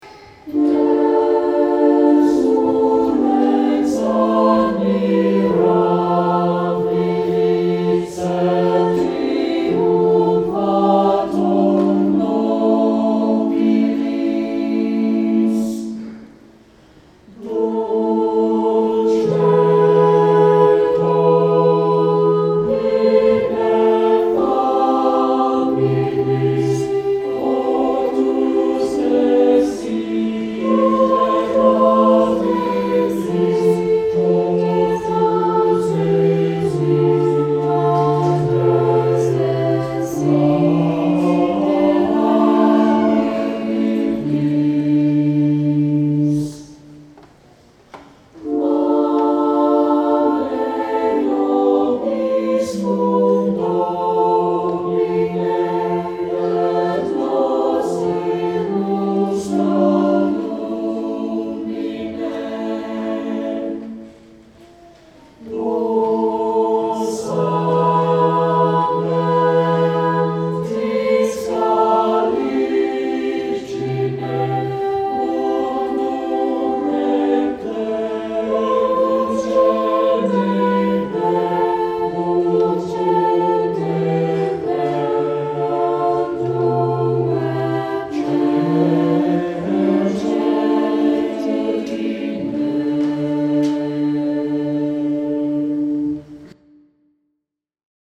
Saint Clement Choir Sang this Song
Anthem